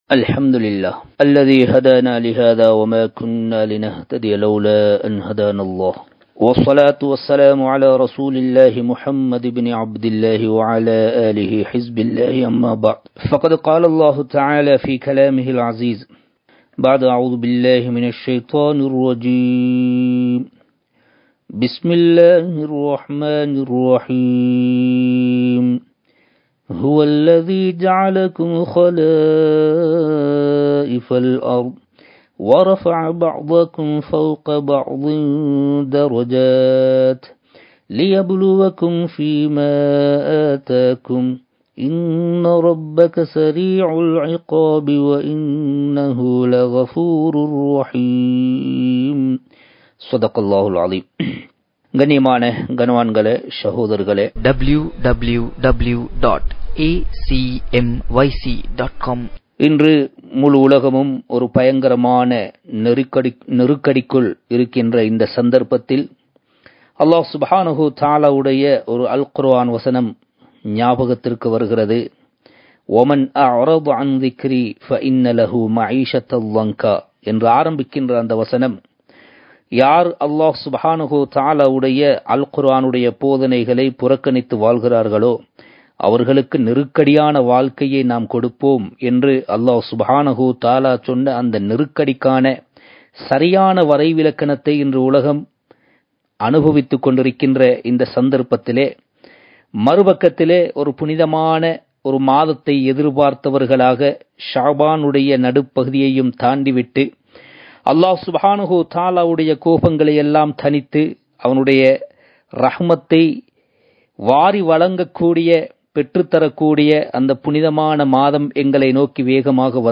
Marumaiel Uthavum Selvangal (மறுமையில் உதவும் செல்வங்கள்) | Audio Bayans | All Ceylon Muslim Youth Community | Addalaichenai
Live Stream